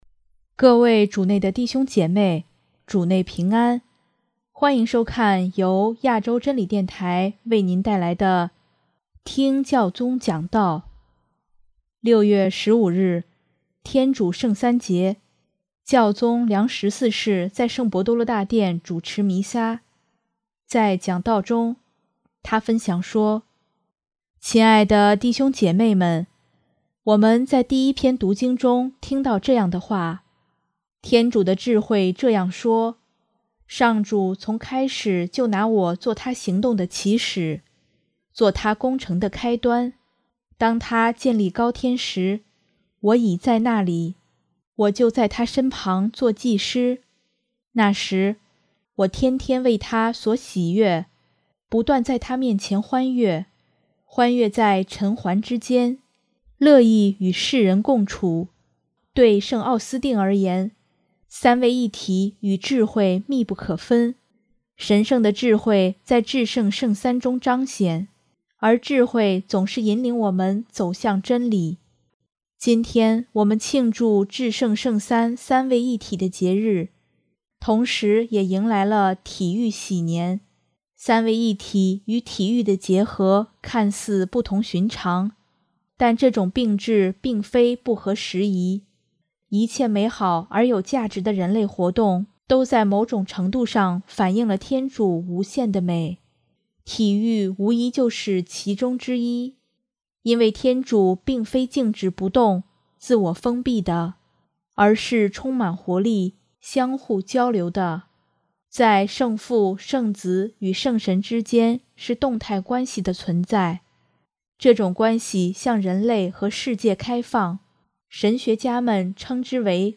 6月15日，天主圣三节，教宗良十四世在圣伯多禄大殿主持弥撒，在讲道中，他分享说：